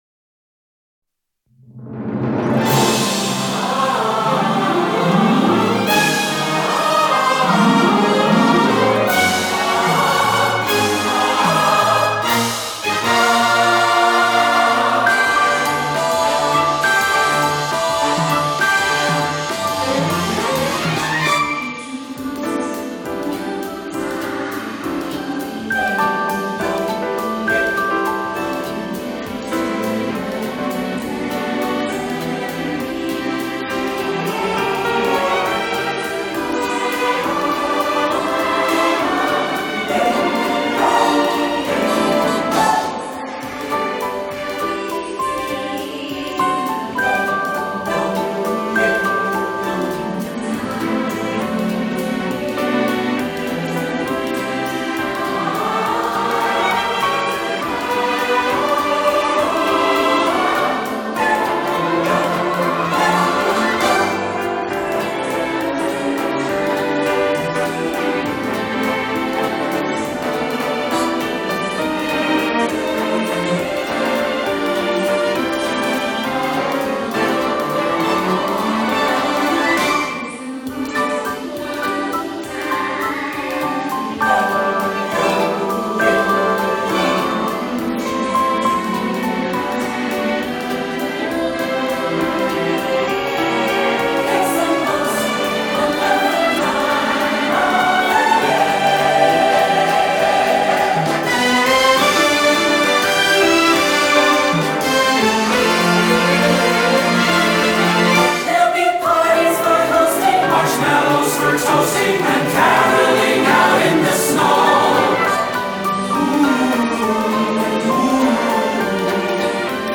Backing Tracks.